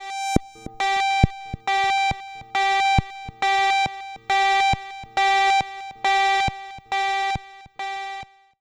58-PULSE  -R.wav